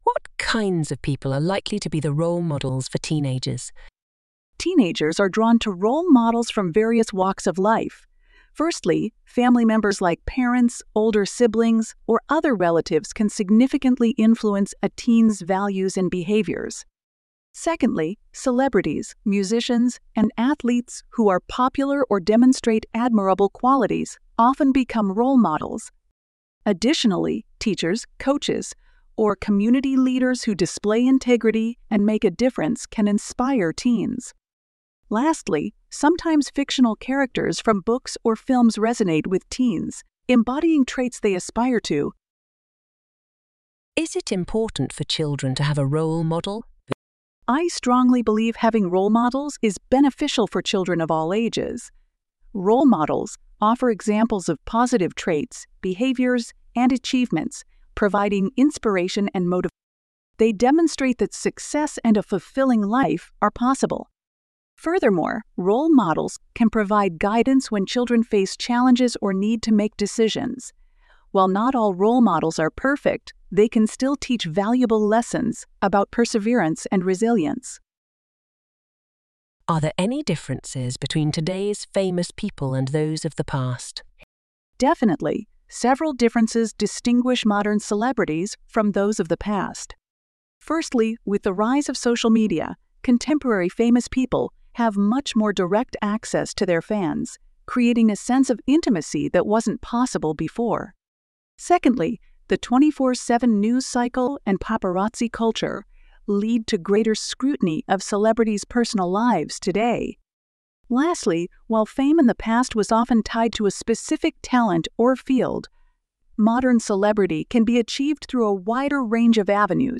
Trong bài viết này, Mc IELTS chia sẻ câu trả lời mẫu band 8.0+ từ cựu giám khảo IELTS, kèm theo các câu hỏi mở rộng kèm theo các câu hỏi mở rộng và bản audio từ giáo viên bản xứ để bạn luyện phát âm, ngữ điệu và tốc độ nói tự nhiên.